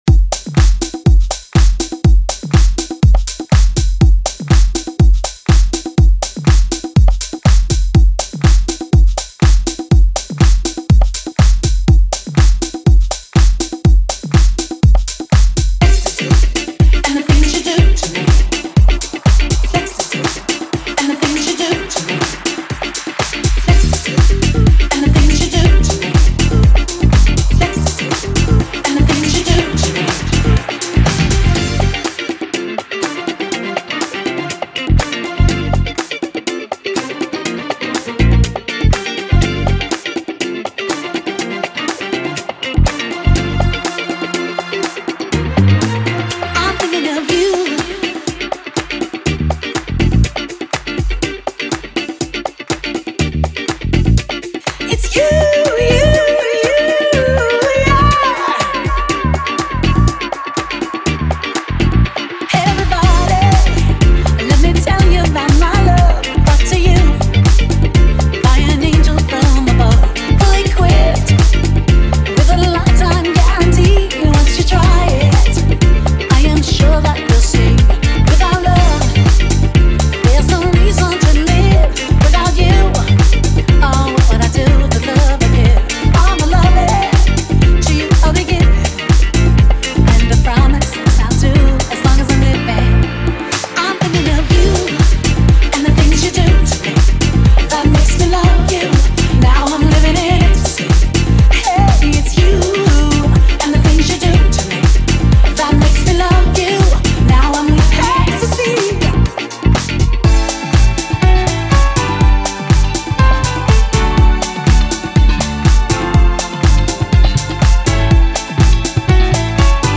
Feel the Rhythm of Miami’s Ultimate House Vibes